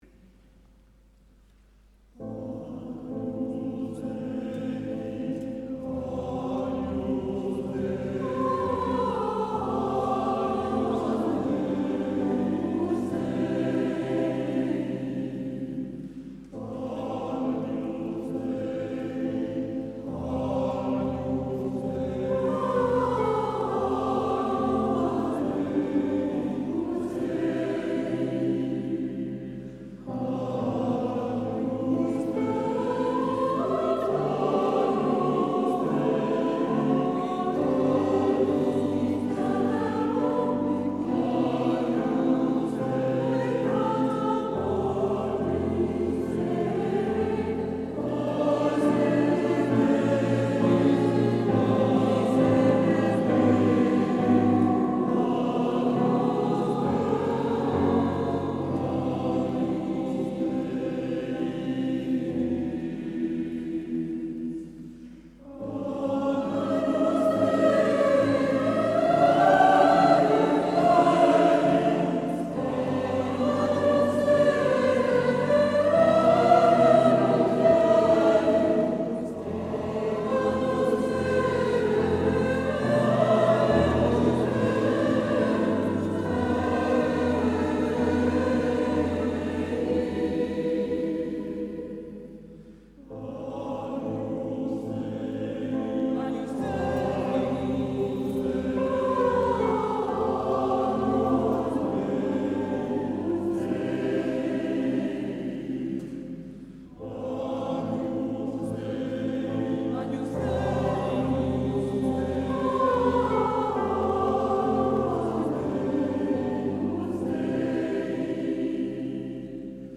A l'occasion du concert caritatif du 9 février 2014 à Bordeaux, église St Louis des Chartrons, l'Ensemble Vocal Hémiole interprétait le Stabat Mater de Karl Jenkins
piano
soliste mezzo-soprano